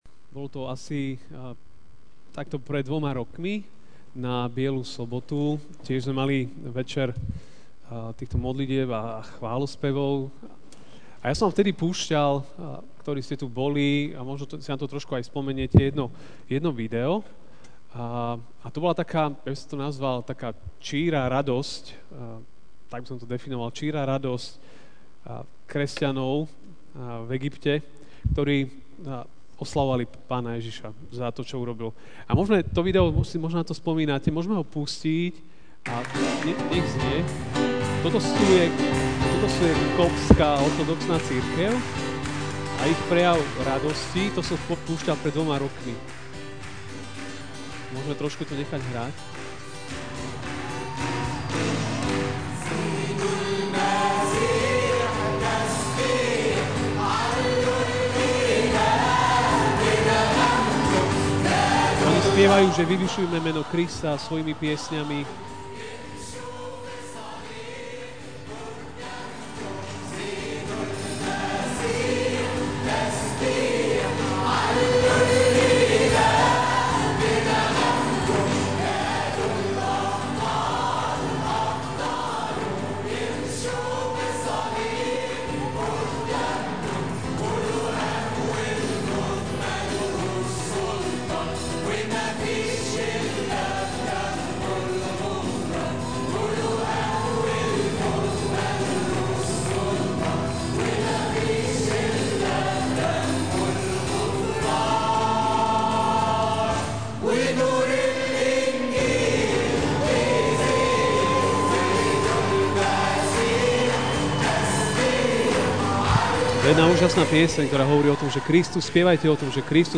Biela sobota - chválový večer: Znovupripojenie (List Filemonovi) Pavel, väzeň Krista Ježiša, a brat Timoteos milovanému Filemonovi, nášmu spolupracovníkovi, sestre Apfii aj Archipovi, nášmu spolubojovníkovi, a zboru, ktorý je v tvojom dome.